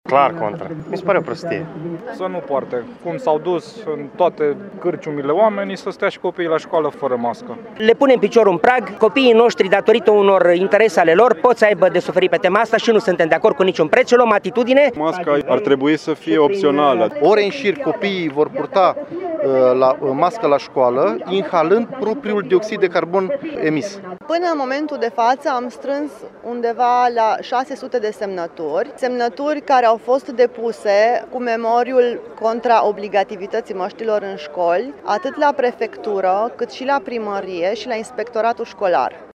vox-uri-protest.mp3